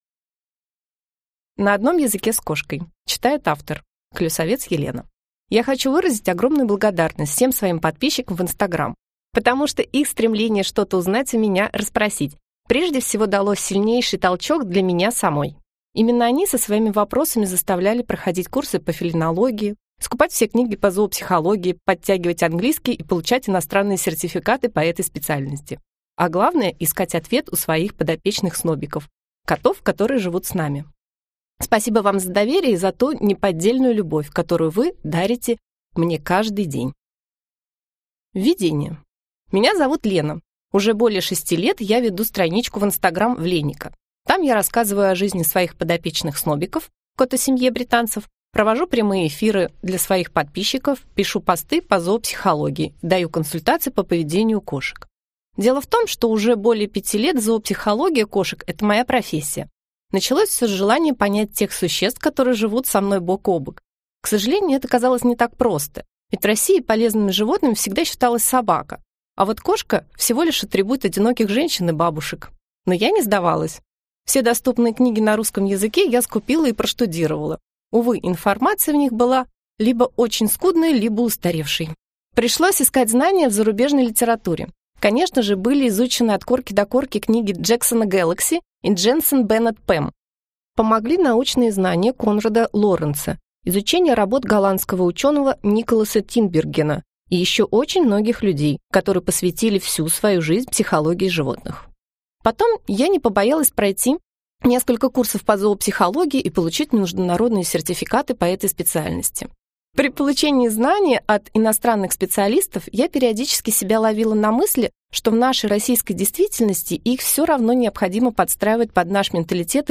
Аудиокнига На одном языке с кошкой | Библиотека аудиокниг